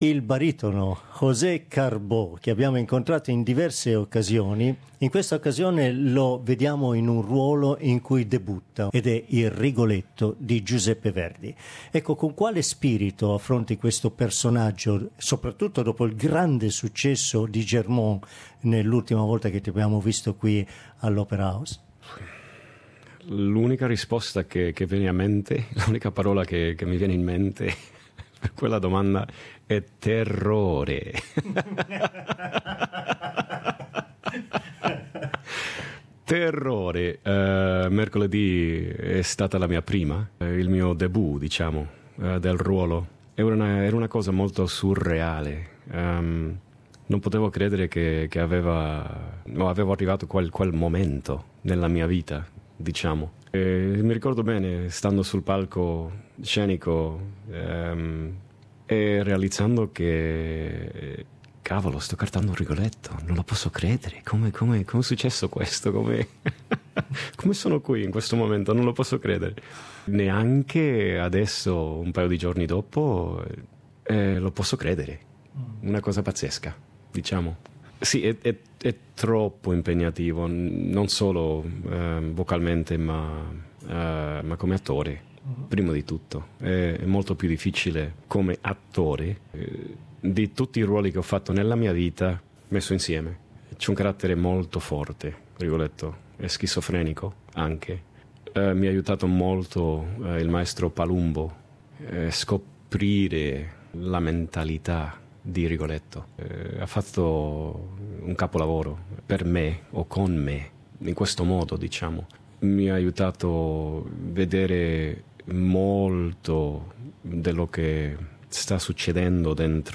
This week we explore the great difficulties that an artist must overcome in order to identify with a character. We do it with baritone José Carbó, who recently sang at the Opera House in Giuseppe Verdi' s"Rigoletto".